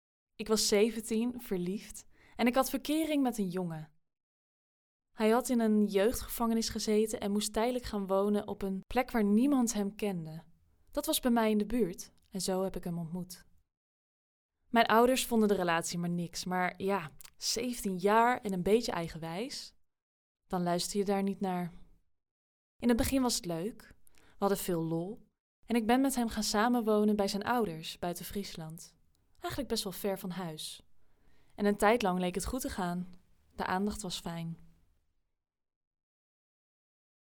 Junge, Natürlich, Verspielt, Zugänglich, Freundlich
Persönlichkeiten
Equipped with a professional home studio, she delivers high-quality recordings with a fast turnaround, ensuring both efficiency and excellence in every project.